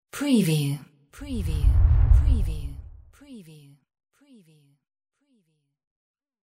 Transition whoosh 22
Stereo sound effect - Wav.16 bit/44.1 KHz and Mp3 128 Kbps
previewTLFE_DISTORTED_TR_WBHD22.mp3